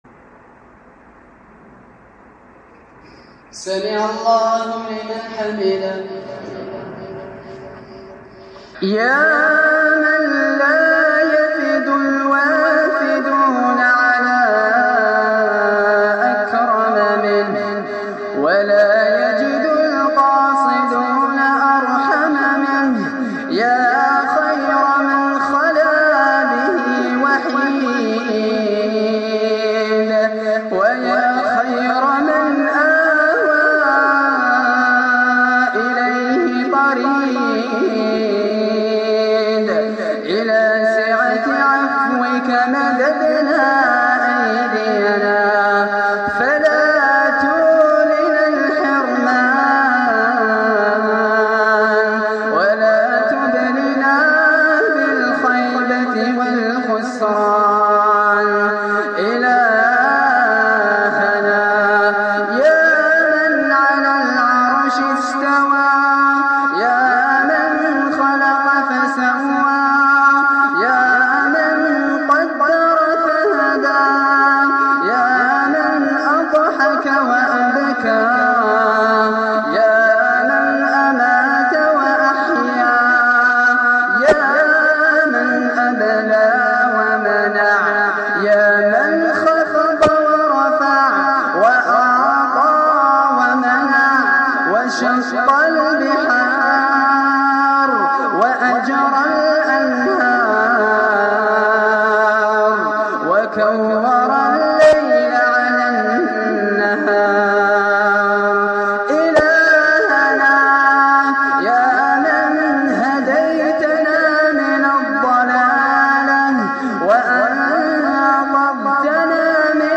دعاء خاشع
تسجيل لدعاء خاشع ومؤثر
في ليلة 27 رمضان